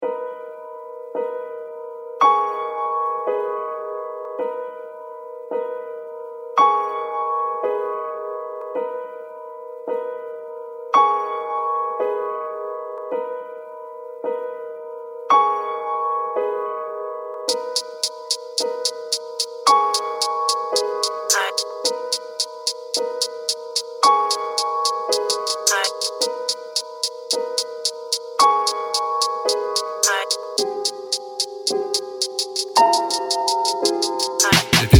Жанр: Рэп и хип-хоп